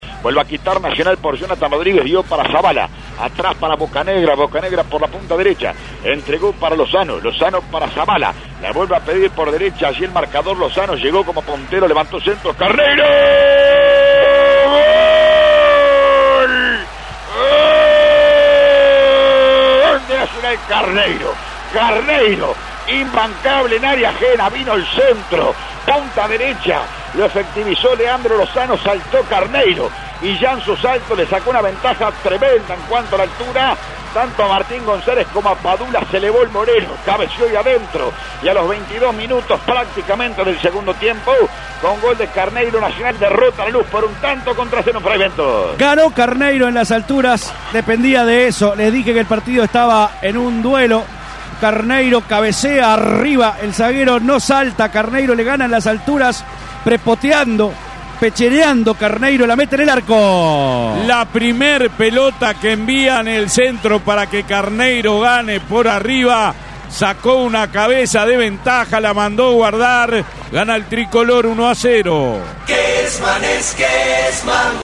ESCUCHÁ EL RELATO DE GOL